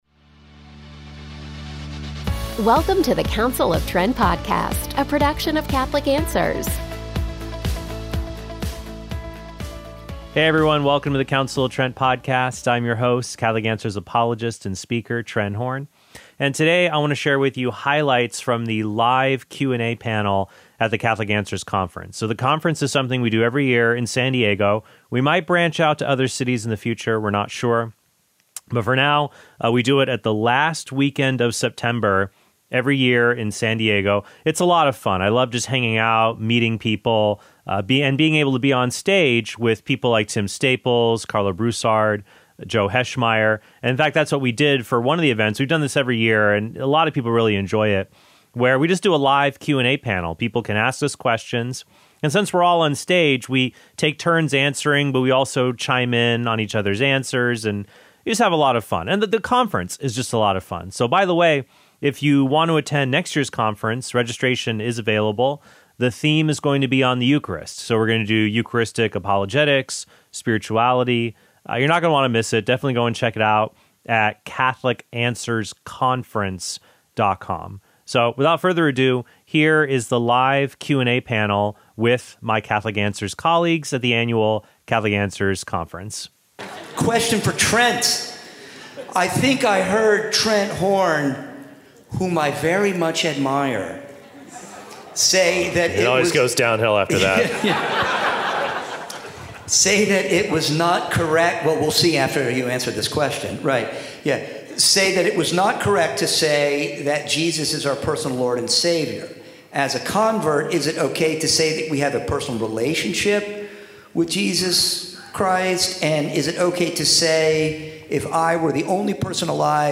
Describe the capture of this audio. Highlights from our 2021 Conference Q+A Panel